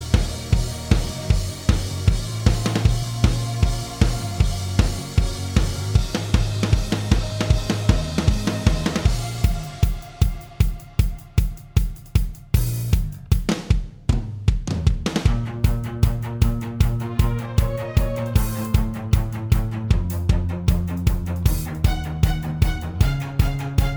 Minus All Guitars Pop (2010s) 3:20 Buy £1.50